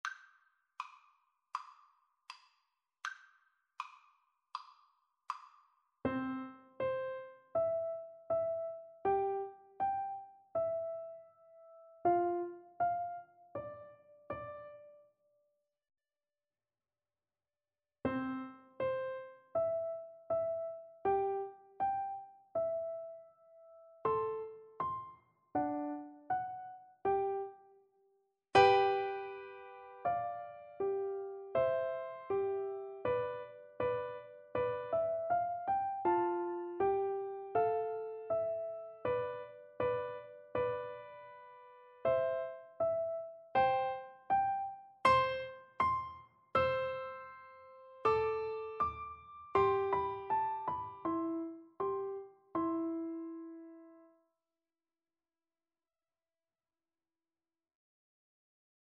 Free Sheet music for Piano Four Hands (Piano Duet)
Andante